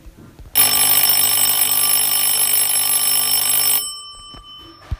Period-style Traditional Door Bell
The bell dome is made from steel and finished in Nickel.
It is rated at 84db.
Grothe-Door-Bell.wav